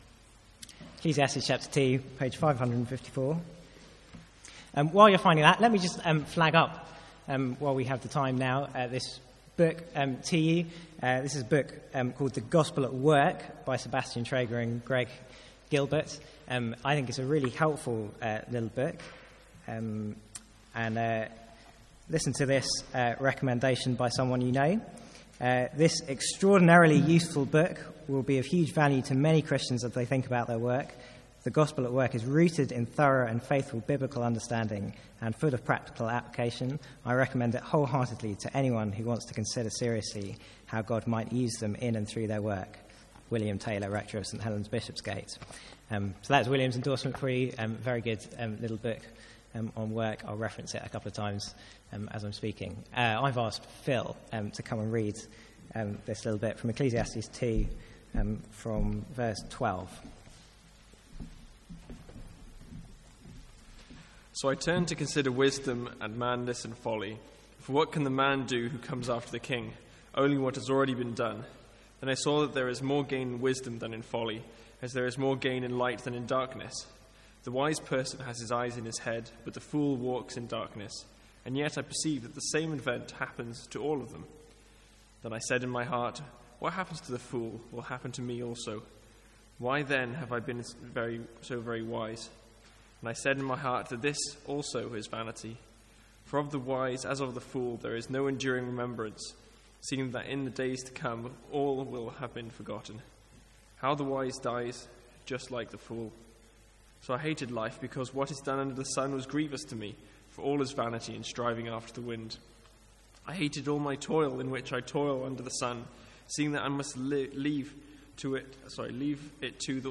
Seminar from MYC16: Identity.